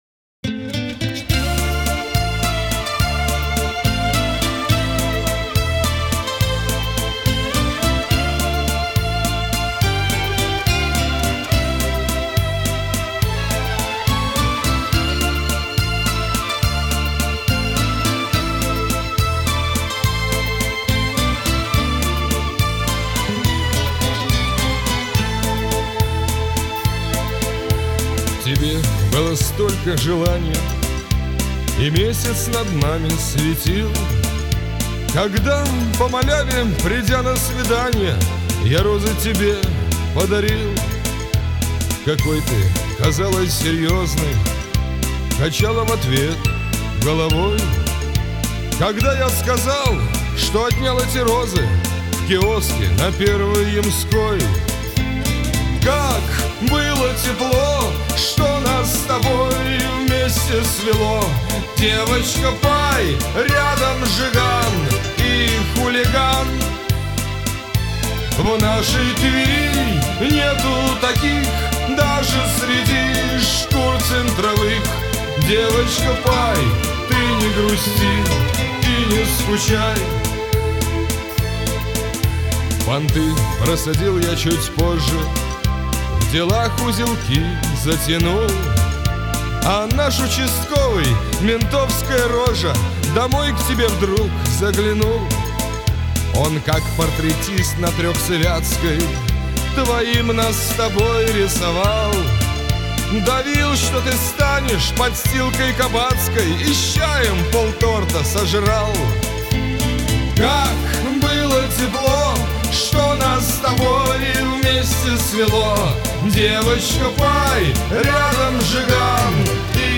Шансон песни
Русский шансон Размер файла